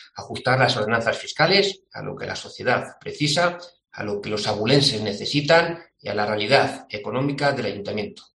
José Ramón Budiño, portavoz Por Ávila. Pleno ordenanzas 2022